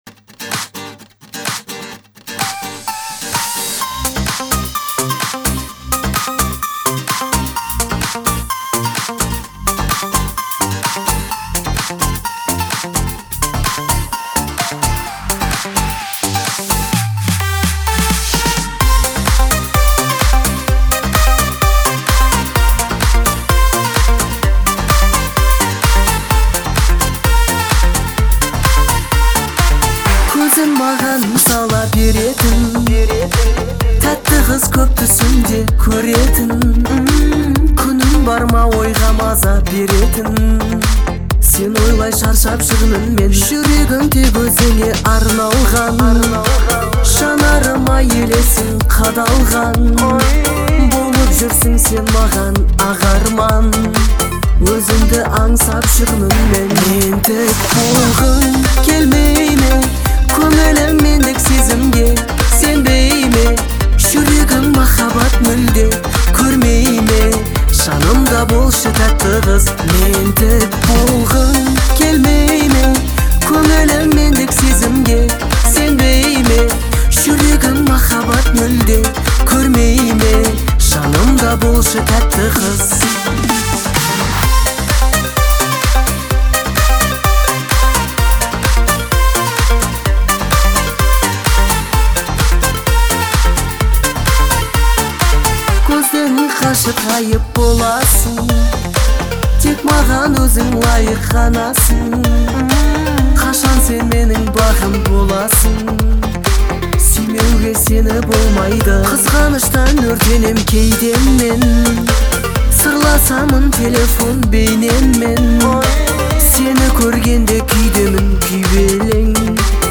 это яркая композиция в жанре казахской поп-музыки